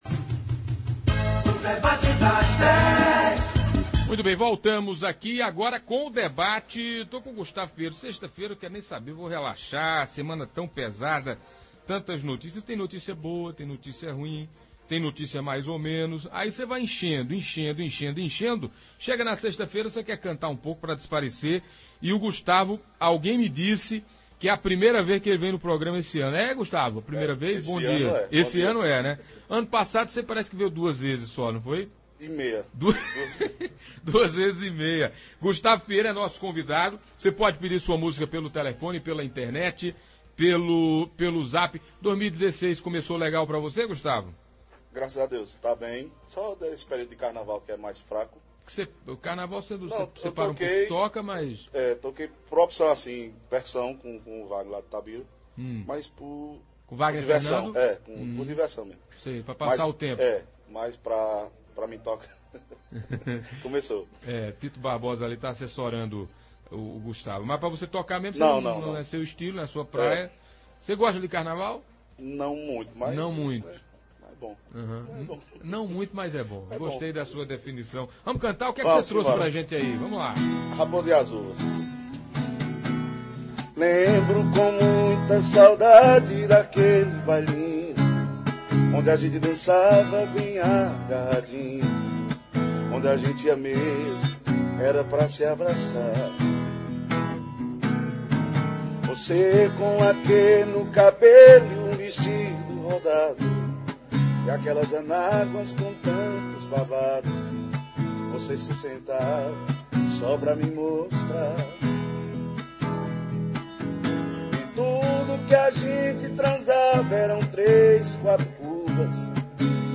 esteve hoje nos estúdios da Pajeú tocando músicas de repertório eclético